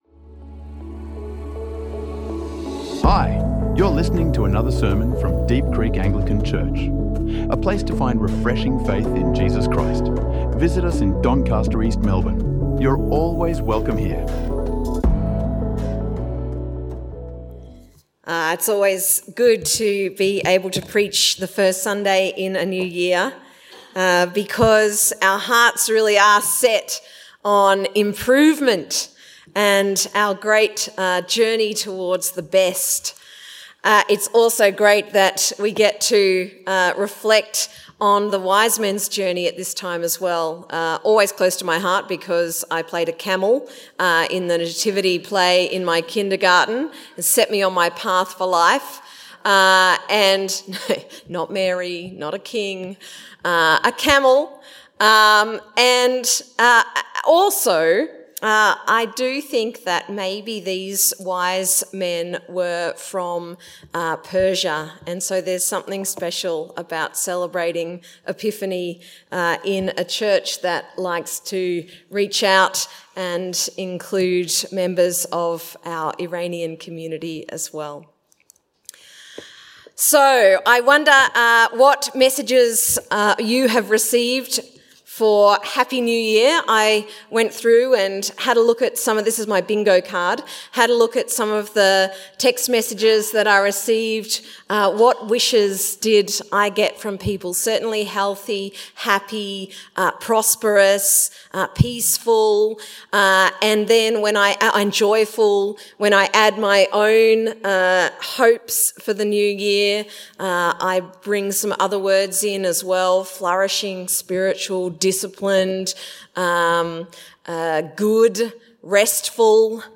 Living Out the Word | Sermons | Deep Creek Anglican Church